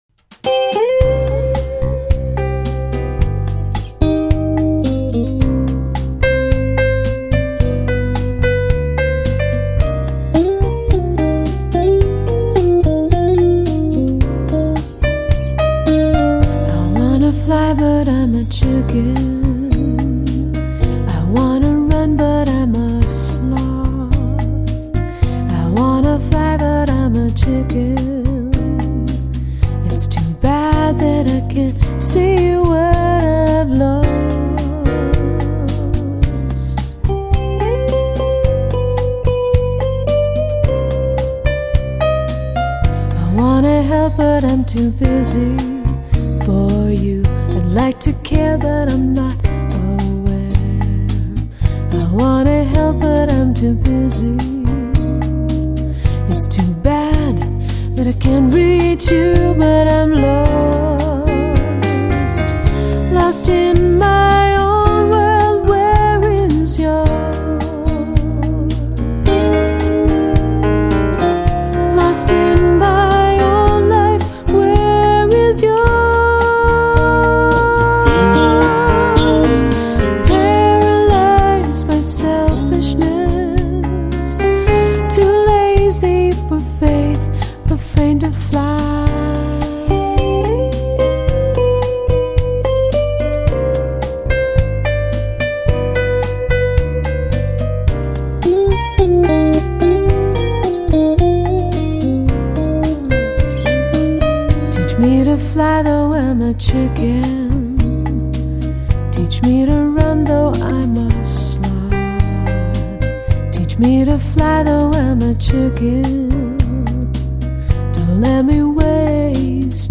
录音效果极佳 声音层次清晰
这是一张12首结合人声、乐器配合拉丁爵士韵味及地方音乐节奏的完美精选集，尽显拉丁音乐的浪漫热情。